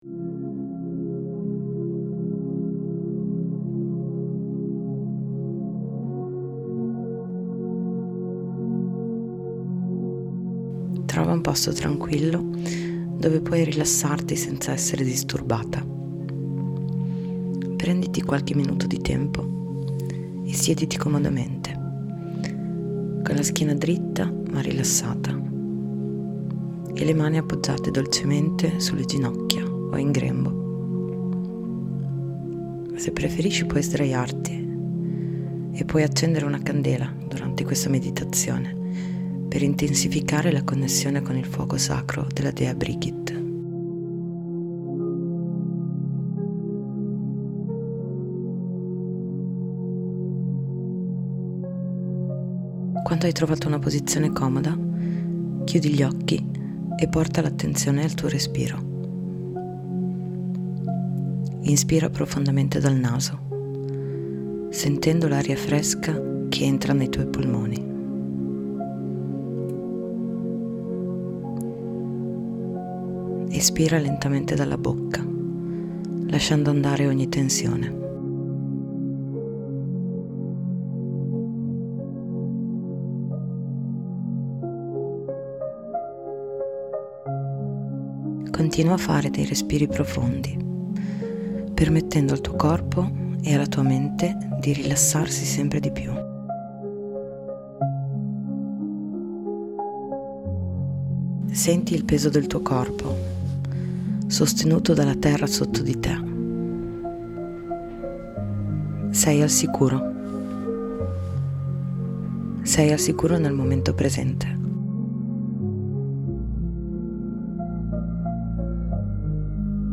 Meditazione-fuoco-interiore.mp3